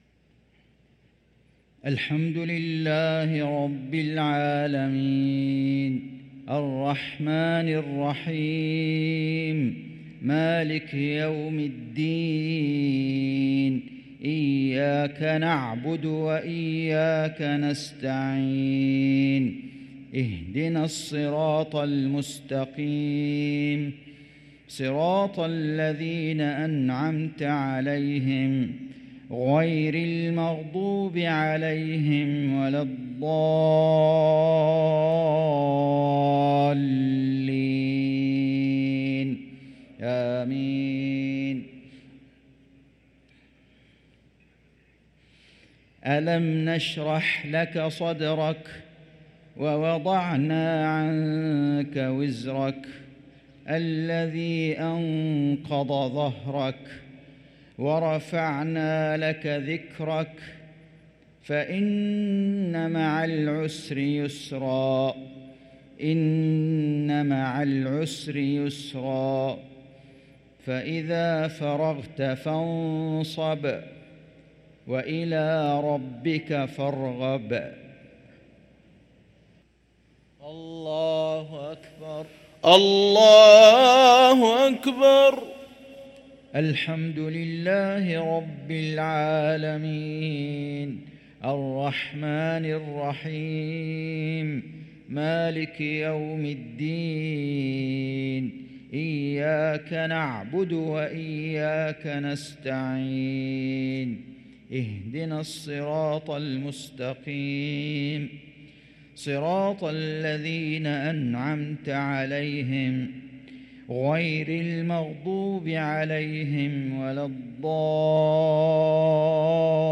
صلاة المغرب للقارئ فيصل غزاوي 3 صفر 1445 هـ
تِلَاوَات الْحَرَمَيْن .